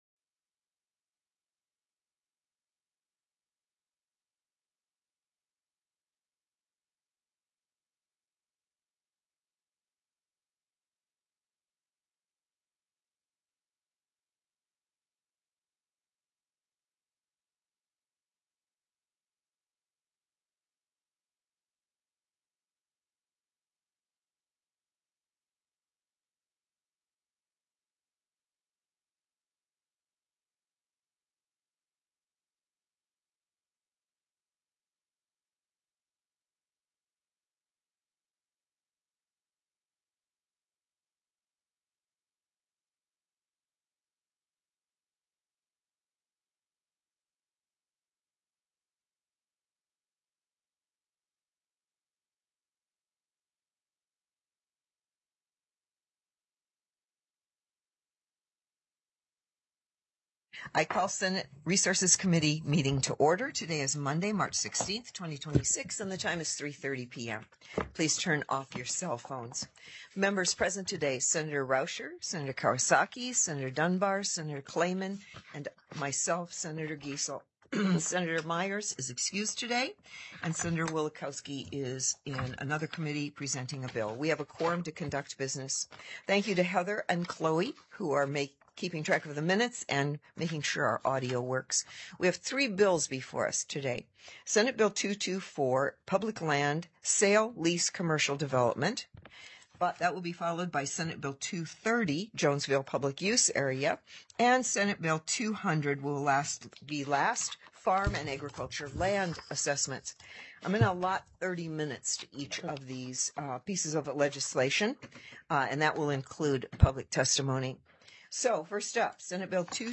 The audio recordings are captured by our records offices as the official record of the meeting and will have more accurate timestamps.
Heard & Held -- Invited & Public Testimony --